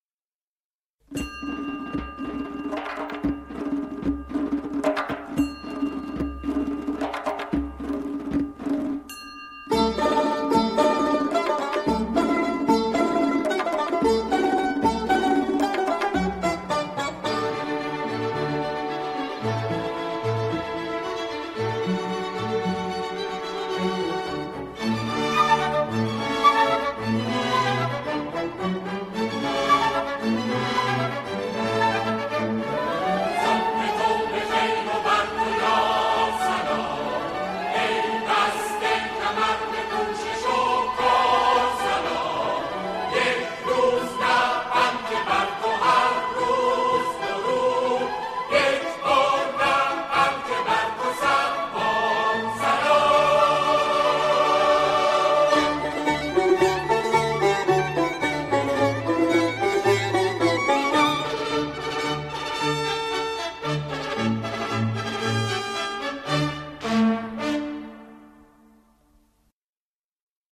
آنها در این قطعه، شعری را درباره عید قربان همخوانی می‌کنند.